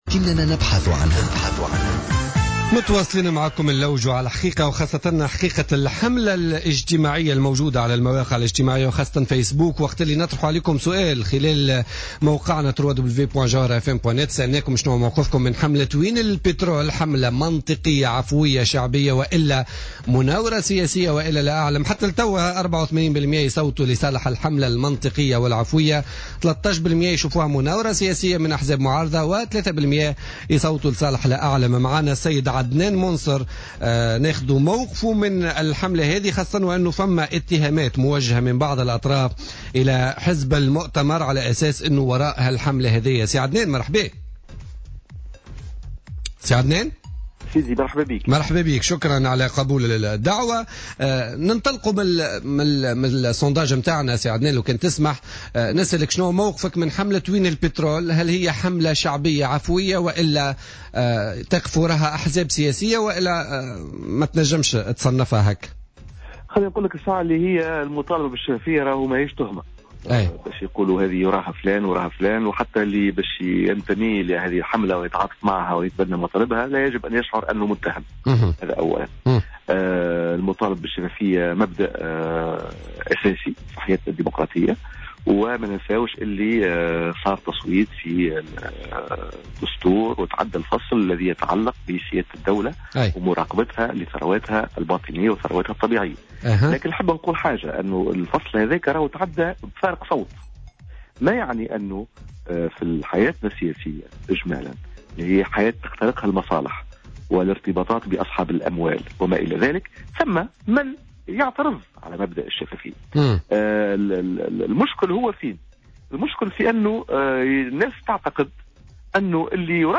أكد القيادي في المؤتمر من أجل الجمهورية عدنان منصر في مداخلة له في بوليتيكا اليوم الجمعة 29 ماي 2015 أن المطالبة بالشفافية في ملف الثروات الطاقية في البلاد والتي بدأت من خلال حملة "وين البترول" الإفتراضية ليست تهمة ولايجب أن يشعر كل من ينتمي لهذه الحملة أو يساندها بأنه متهم على حد قوله.